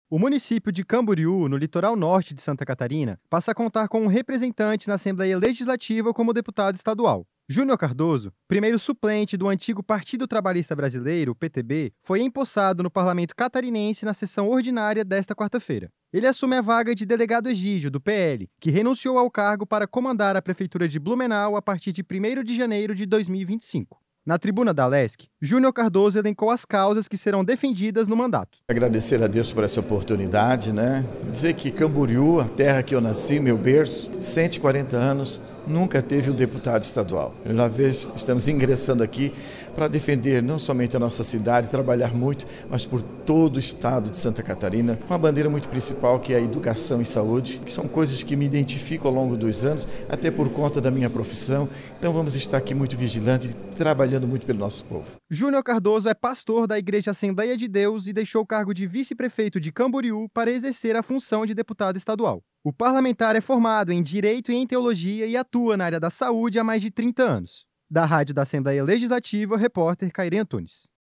Entrevista com:
- deputado Júnior Cardoso.